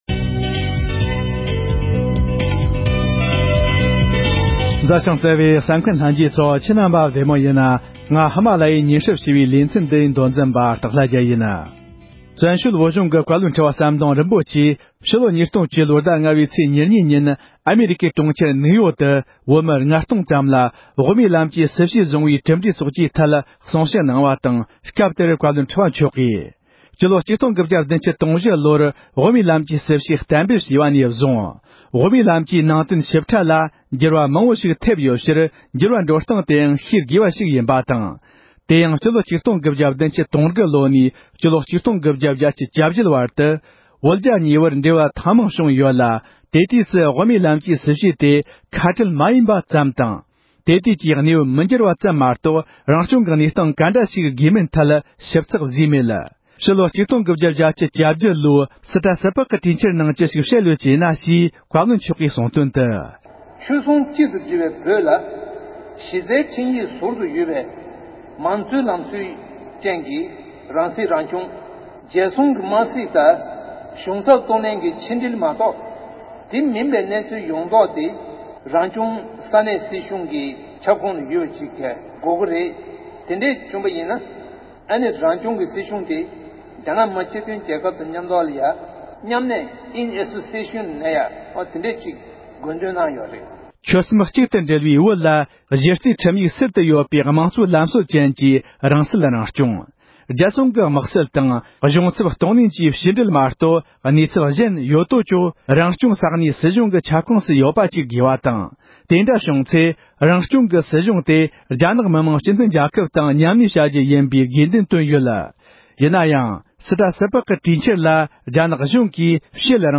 བཀའ་ཁྲི་མཆོག་གིས་ཨ་རིའི་གྲོང་ཁྱེར་ནིའུ་ཡོརྐ་ཏུ་བོད་མི་ལྔ་སྟོང་ལྷག་ལ་དབུ་མའི་ལམ་གྱི་སྲིད་བྱུས་ཀྱི་འགྱུར་བ་དང་གྲུབ་འབྲས་སྐོར་གསུང་བཤད།
སྒྲ་ལྡན་གསར་འགྱུར། སྒྲ་ཕབ་ལེན།